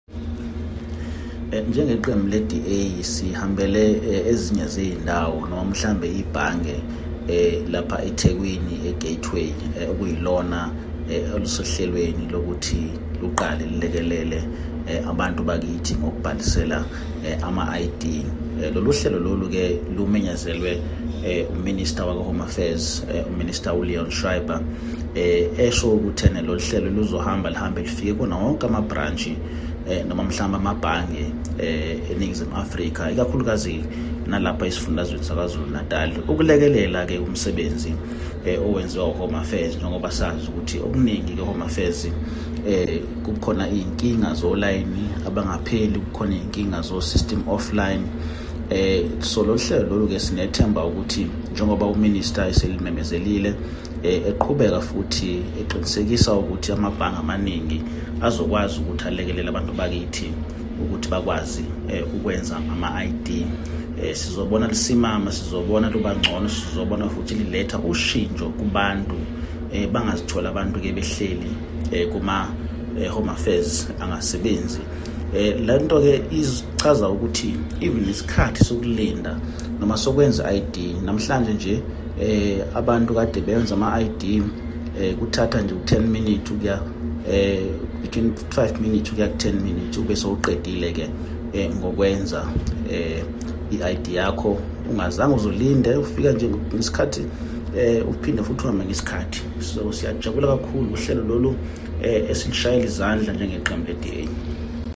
isiZulu by Mzamo Billy MP.
Mzamo-Billy-MP_isiZulu-1.mp3